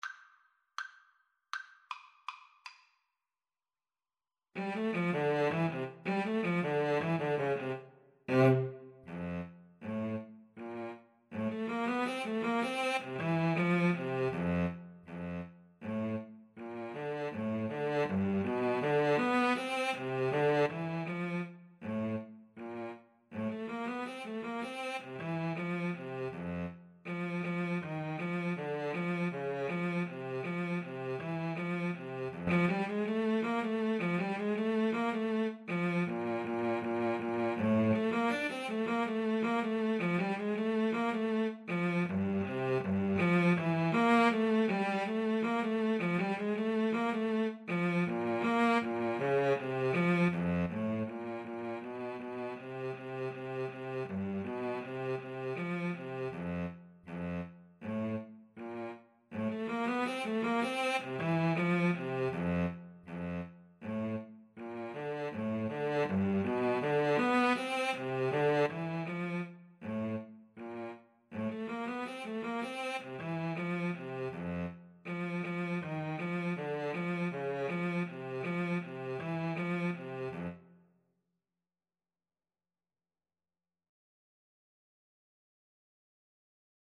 Free Sheet music for Clarinet-Cello Duet
F major (Sounding Pitch) G major (Clarinet in Bb) (View more F major Music for Clarinet-Cello Duet )
=180 Presto (View more music marked Presto)
Jazz (View more Jazz Clarinet-Cello Duet Music)